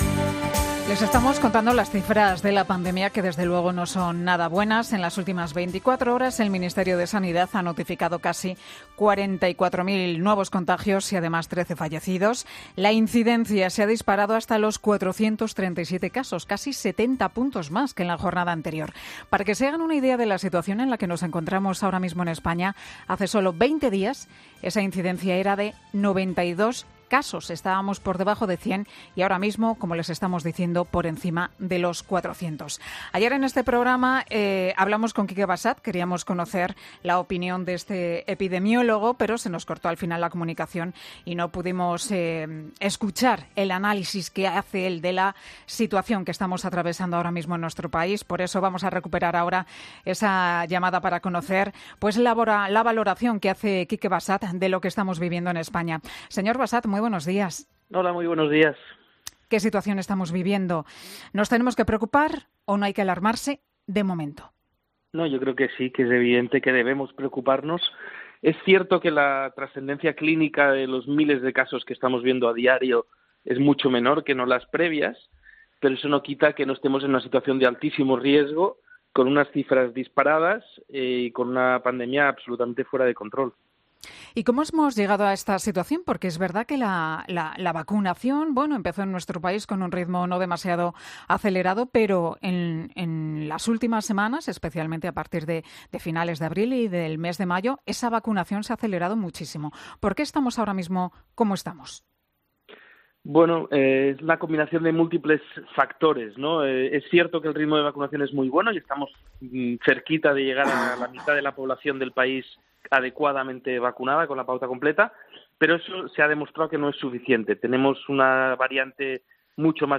El epidemiólogo sostiene en 'Herrera en COPE' la necesidad de seguir tomando medidas restrictivas para frenar los contagios